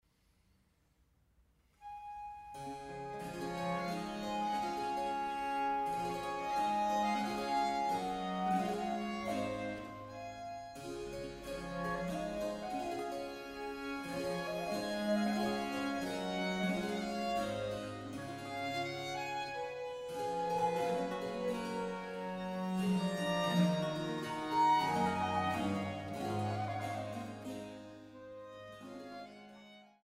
Laute
Viola da Gamba
Flöte/Dulzian
Violine/Viola